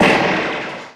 VEC3 Percussion 056.wav